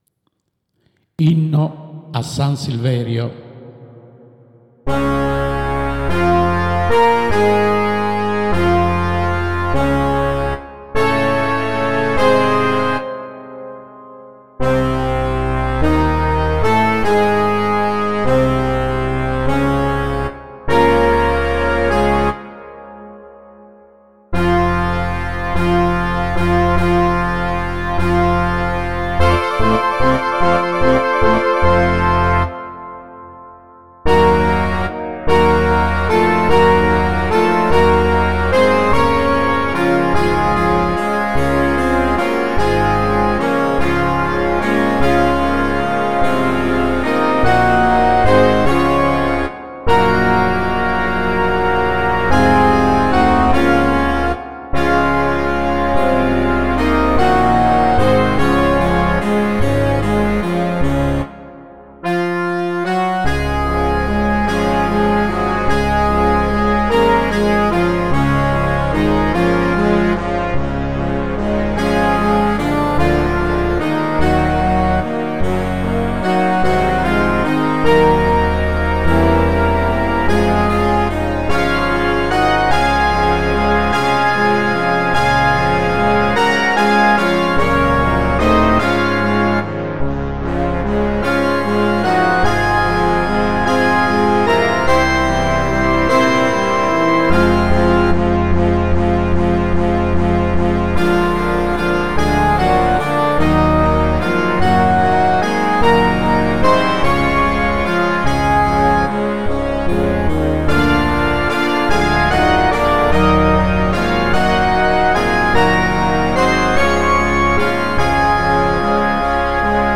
ricordato che non ti ho mai mandato L’inno classico di San Silverio, suonato dalla banda come
Io da vecchia data lo avevo elaborato e fatto suonare per banda e credo ti piaccia, perchè ti ricorderà i vecchi tempi.